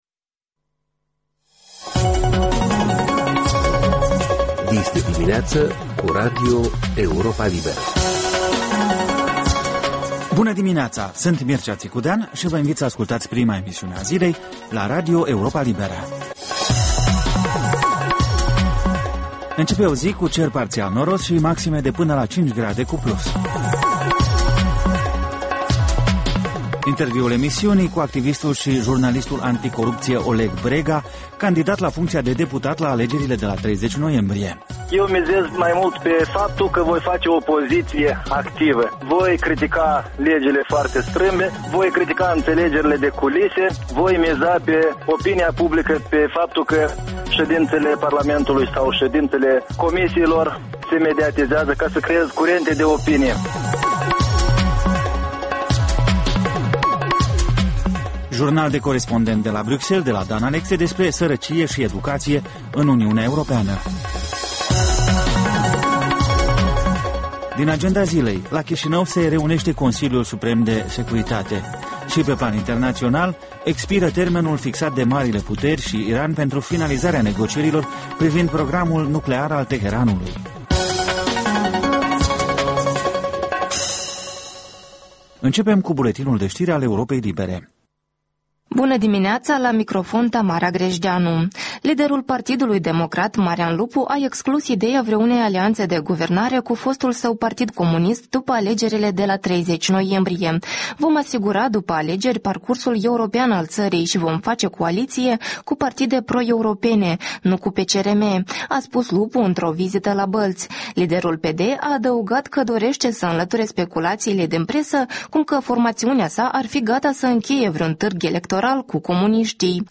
Ştiri, informaţii, interviuri, corespondenţe.